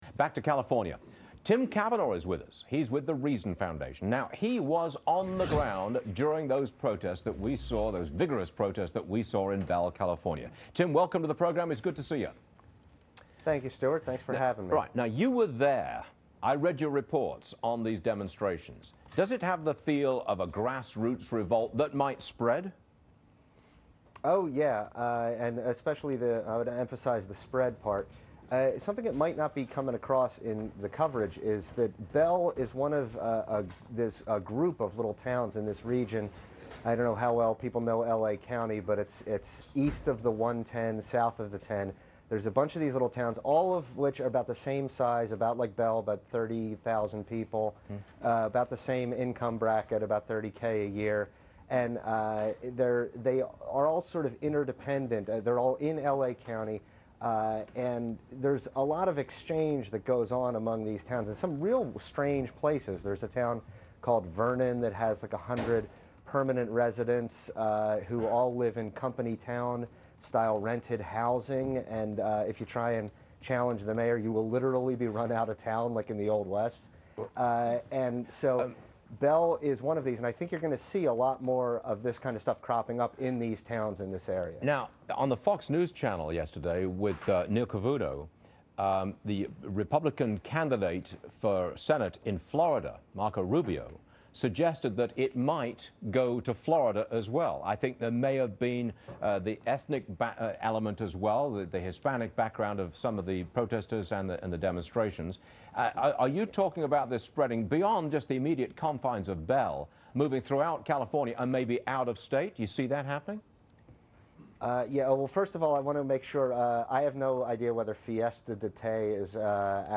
appeared on Varney & Co. on the Fox Business Network on July 28, 2010.